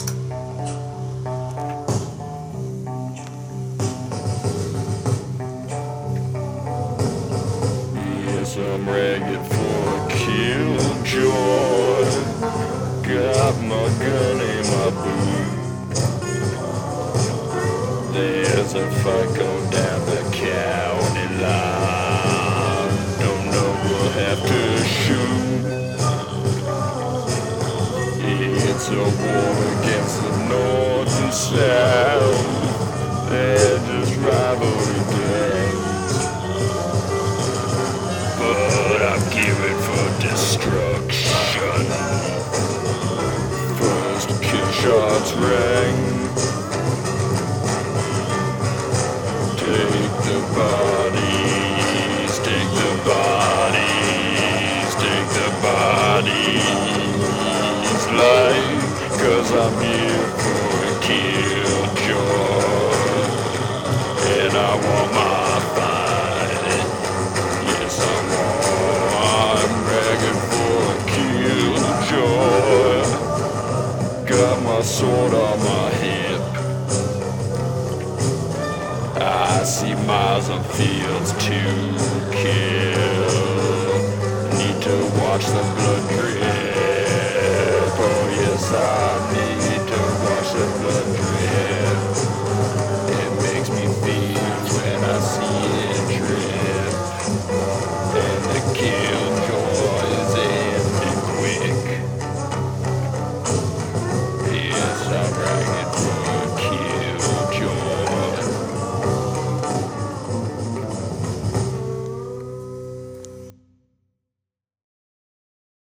Two-piece band
Recorded at the Hells Angels club house, New York City.